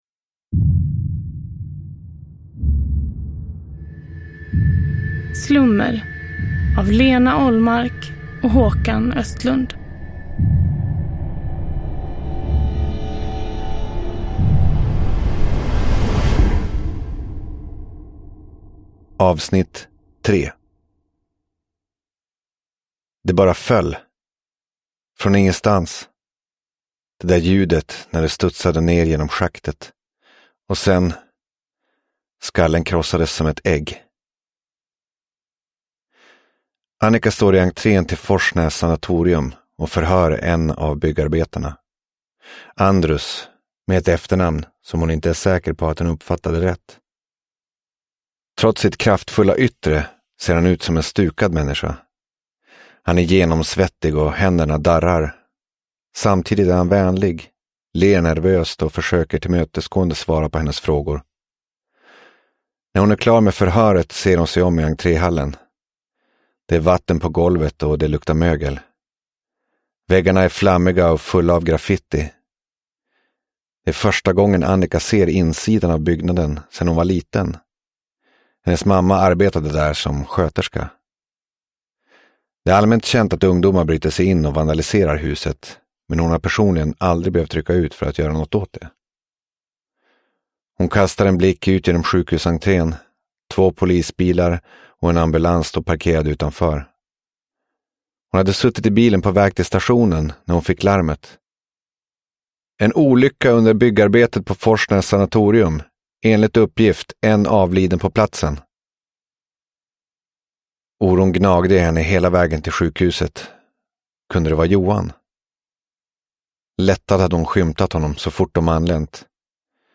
Slummer - Del 3 – Ljudbok – Laddas ner